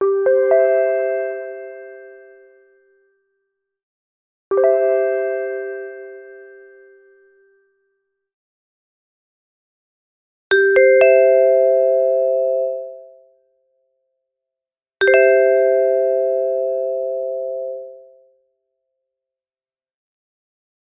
Call Signal Announ
airport announcement attention beep call PA supermarket tone sound effect free sound royalty free Sound Effects